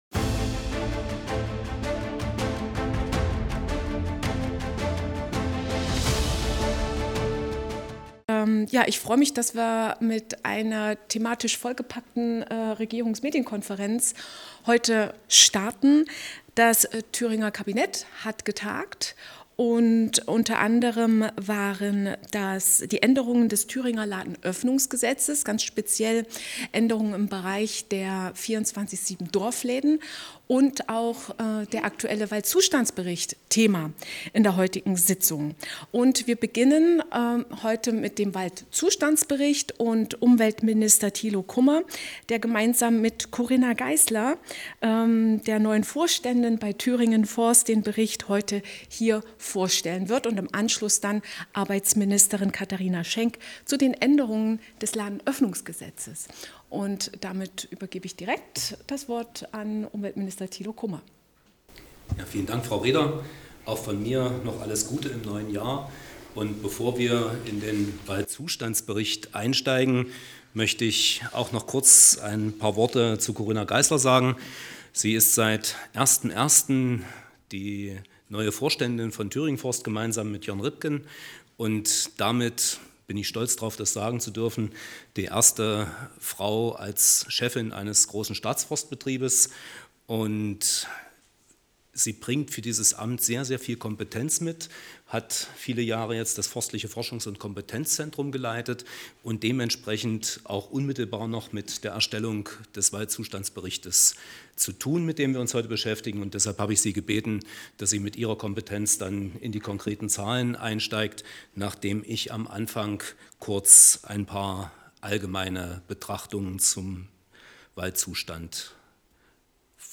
Letzterer versteht es hervorragend sein Publikum im Weimarer Palais Schardt geistig aufzulockern.